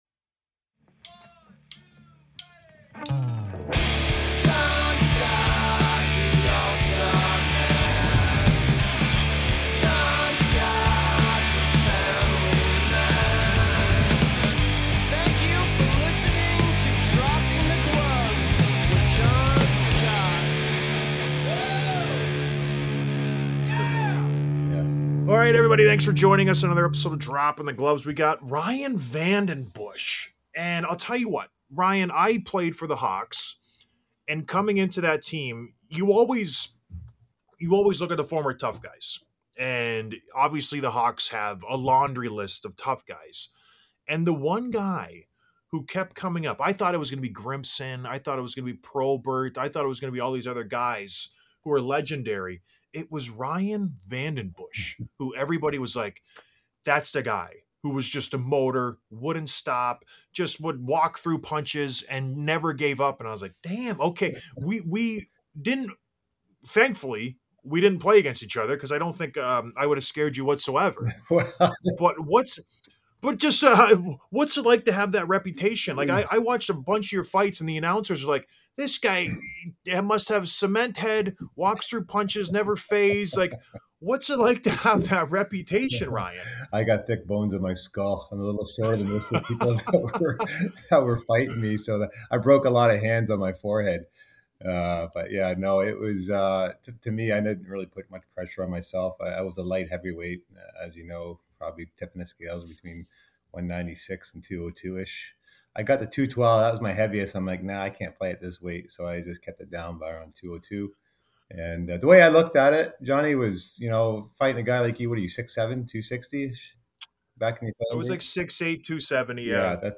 Interview w